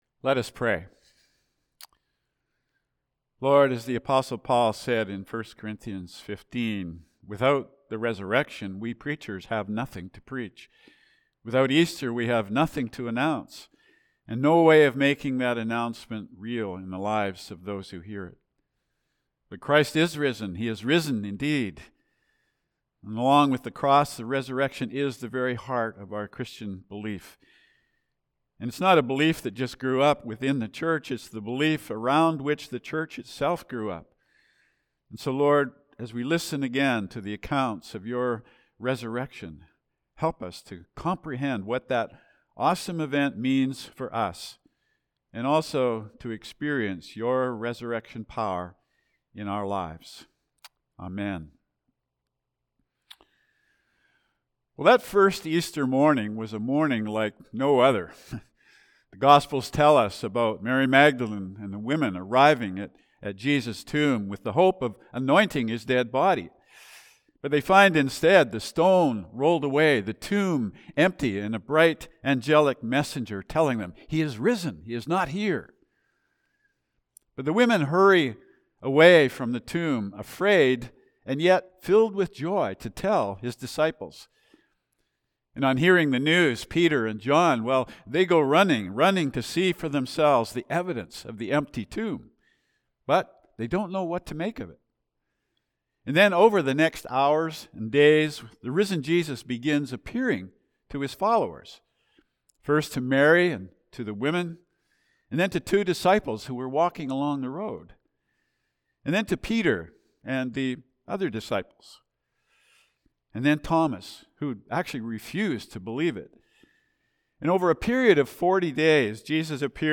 Easter Sunday Message: Where Jesus Meets Us – Calvary Baptist Church of Gibsons